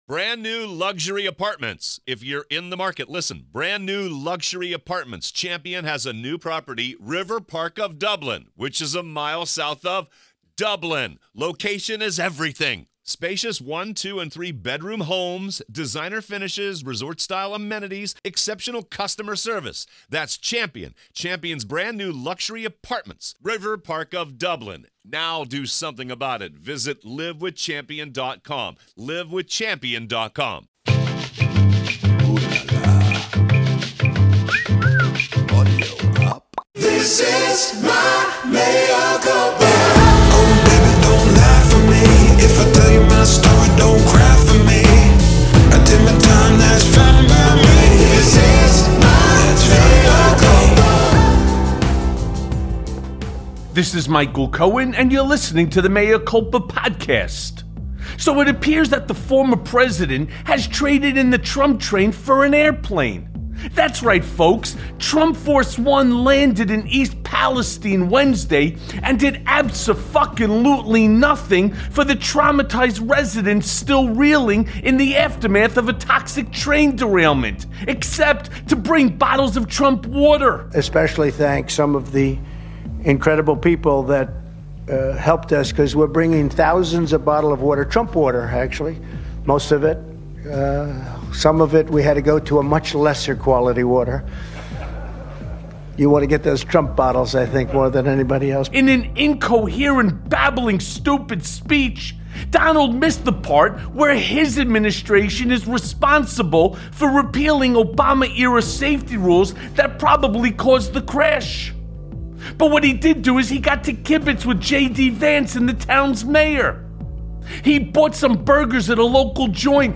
Mea Culpa welcomes back our old friend Malcolm Nance. Nance has just returned from the frontline in Ukraine and has a lot to say about the current situation there.